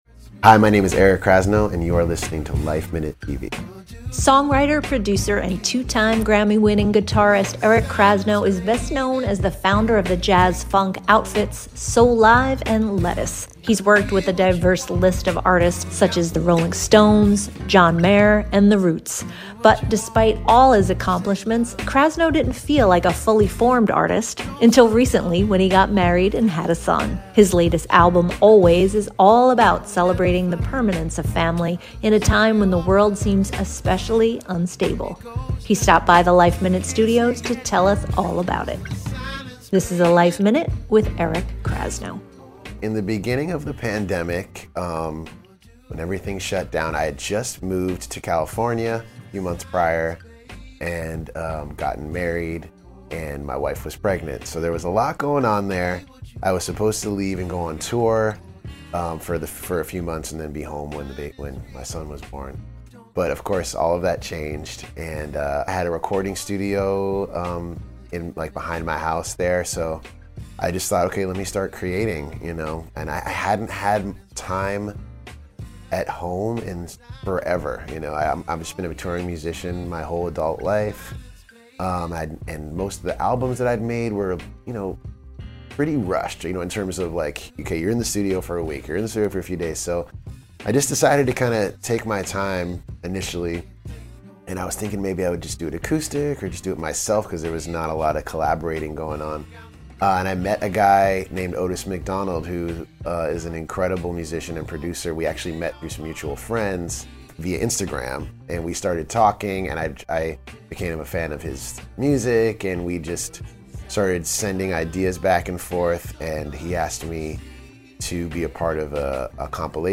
His latest album, Always , is all about celebrating the permanence of family in a time when the world seems especially unstable. He stopped by the LifeMinute studios to tell us all about it.